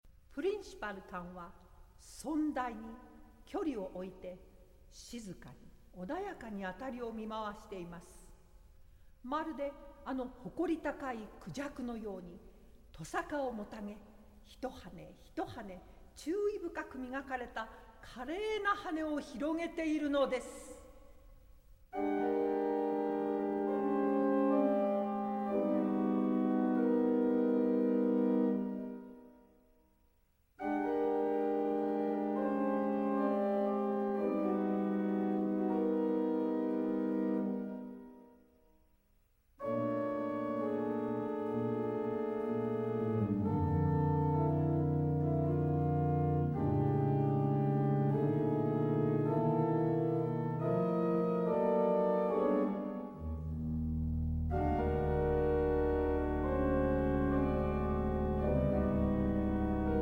organ
percussion
Japanese narrator